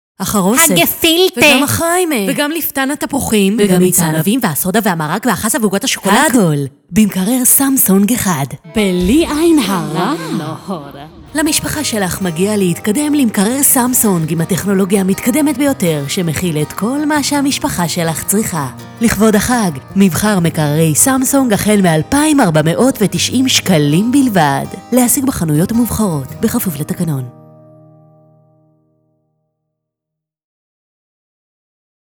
שת"פ שלנו עם פרסום דמיון - תשדיר ל"סמסונג"
אעלה בהמשך בעז"ה. קבצים מצורפים סמסונג נשי מלא.wav 5.4 MB · צפיות: 25